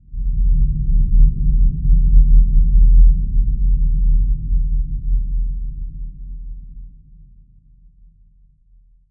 地震
描述：用Audacity生成的非常低频的隆隆声；可以用来制造远处地震的声音，但要记住你需要非常大的扬声器来呈现这种效果。
Tag: 地震 低频 地震 隆隆